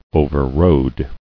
[o·ver·rode]